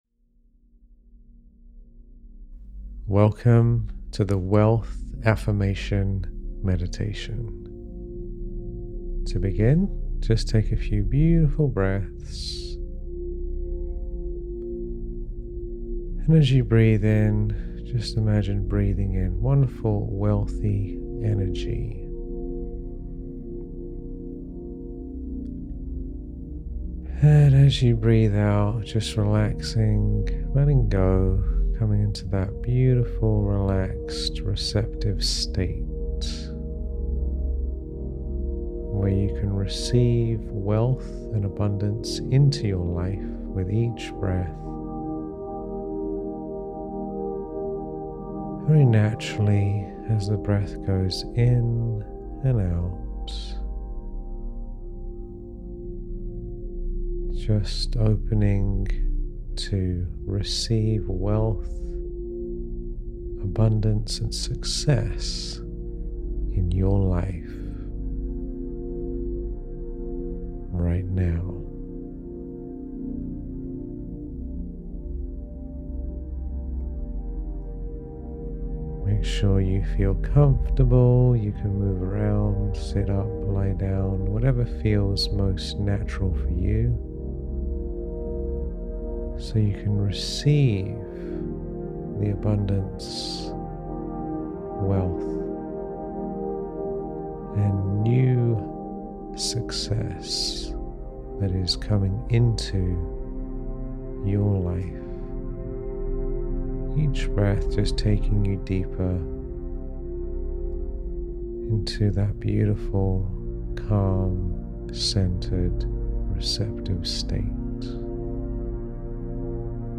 Designed to align your mind, body, and spirit with the frequency of wealth, this meditation uses guided affirmations to dissolve limiting beliefs and invite success into every area of your life.